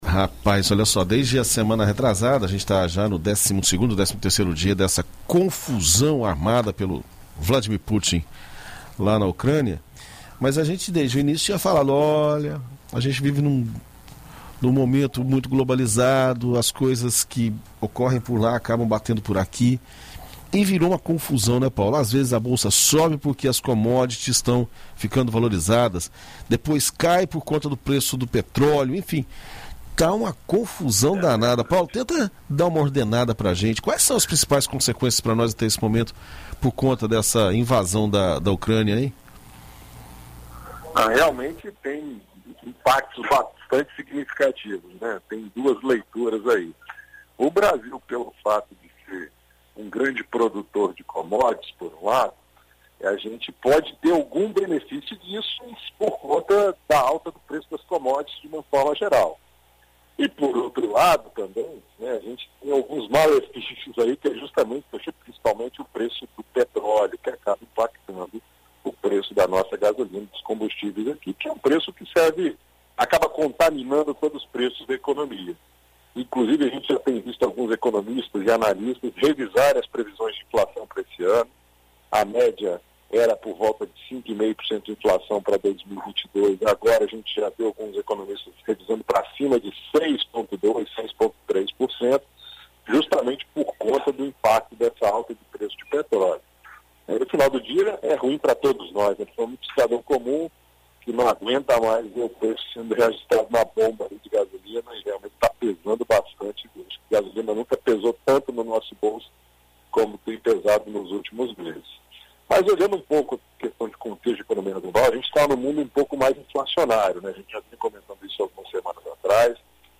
Na coluna Seu Dinheiro desta terça-feira (08), na BandNews FM Espírito Santo